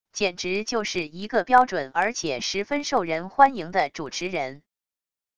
简直就是一个标准而且十分受人欢迎的主持人wav音频